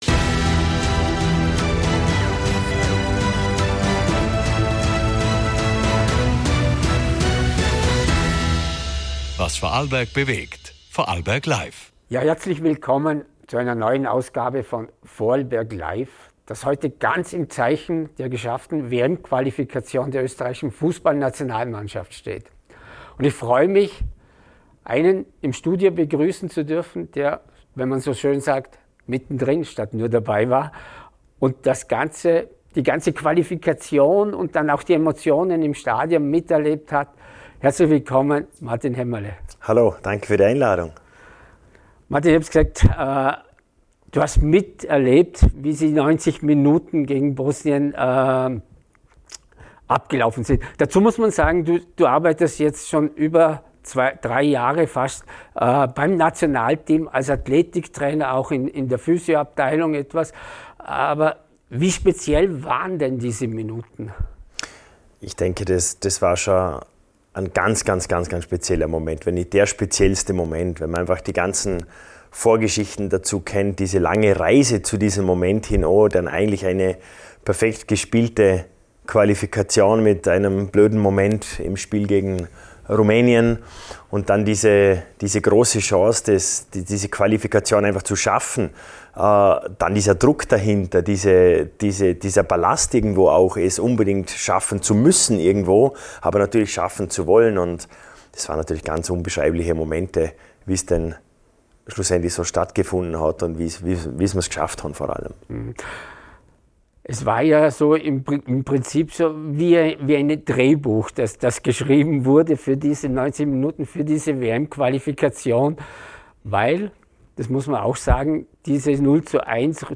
Das Gespräch beleuchtet: – Der Weg zur Qualifikation und die entscheidenden 90 Minuten gegen Bosnien – Was sich im Trainings‑ und Betreuungsalltag geändert hat – von Ernährung bis Regeneration – Wie das Team‑Gefüge zur Familie wurde und welche Anforderungen vor einer WM warten Für alle, die verstehen wollen, wie Spitzensport funktioniert – jenseits des Spielfelds und mitten im System.